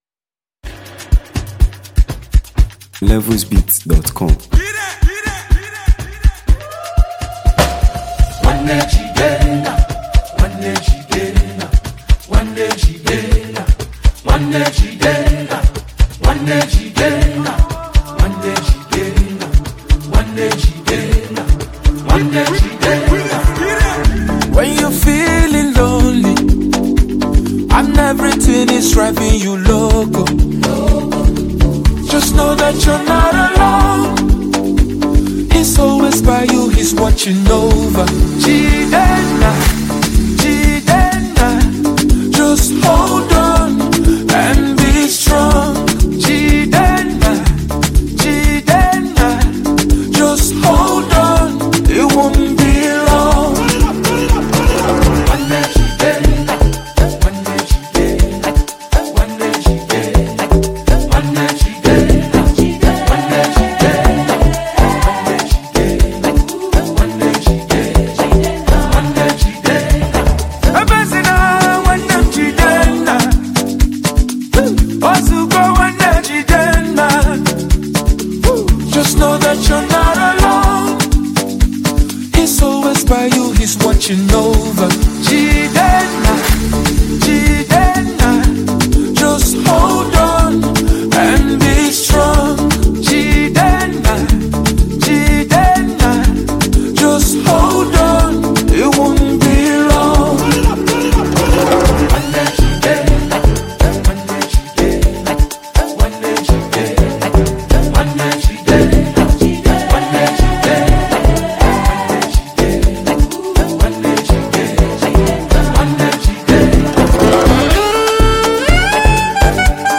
Indigenous Highlife
and mastery of traditional highlife rhythms
blend indigenous soundscapes with modern musical elements
vibrant instrumentation